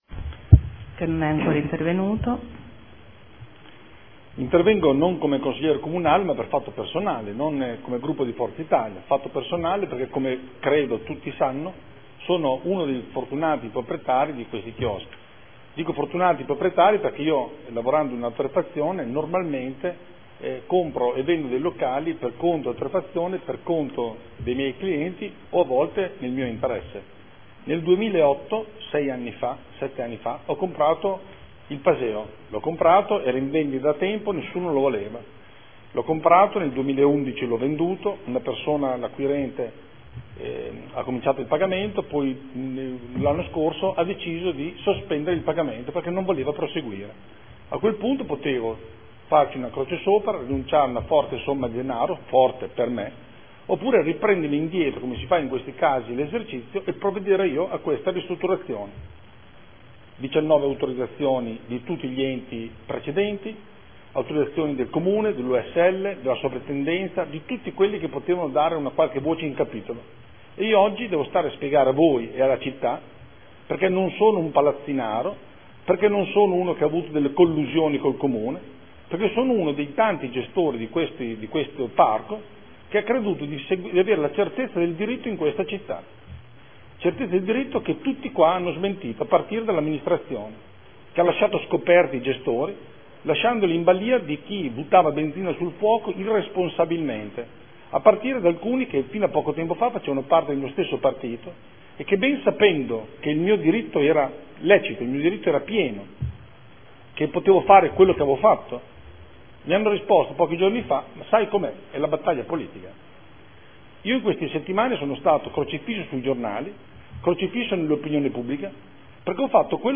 Seduta del 20/03/2014 Dibattito su interrogazioni 11 12 17